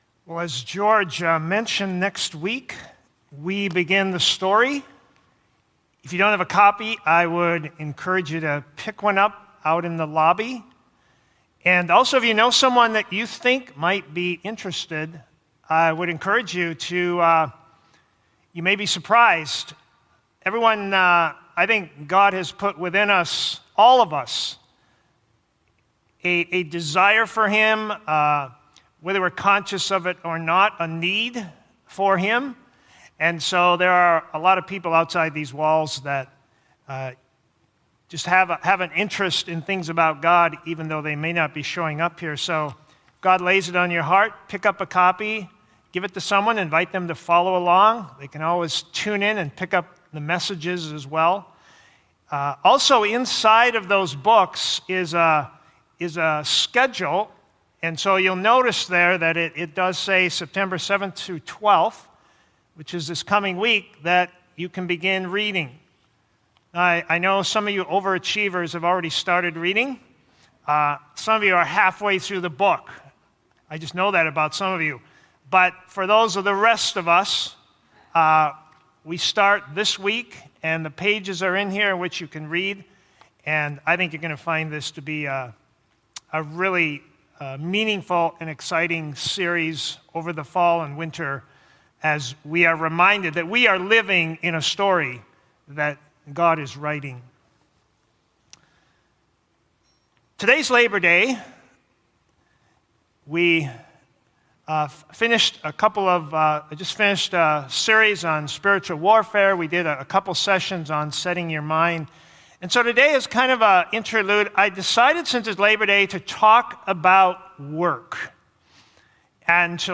First-Baptist-Sermon-September-6-2020.mp3